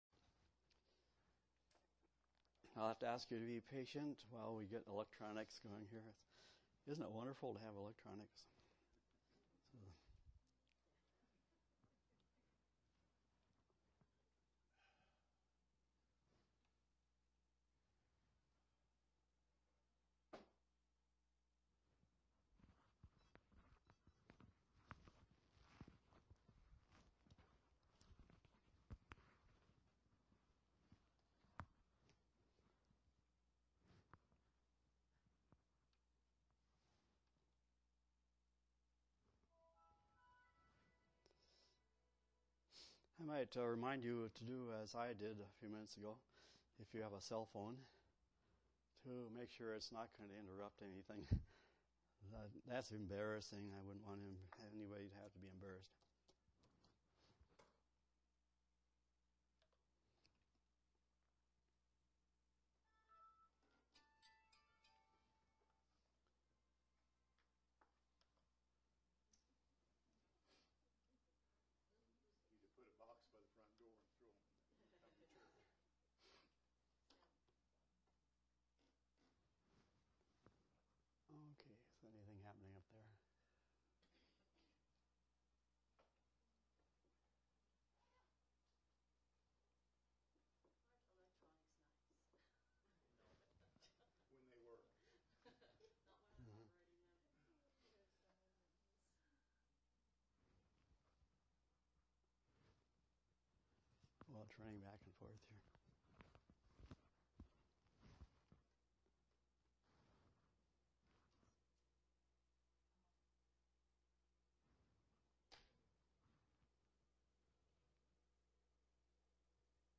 5/12/2013 Location: Collins Local Event